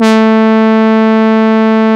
OB8 SAW 4 00.wav